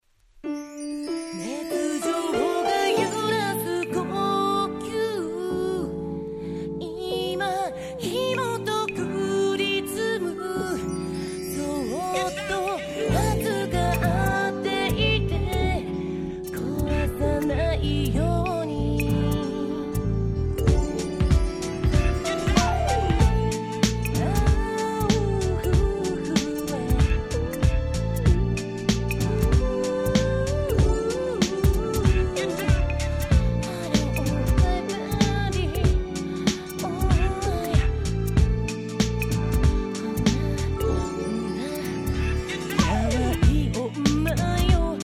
【Media】Vinyl 12'' Single (新品未開封!!)
Japanese R&B Classic !!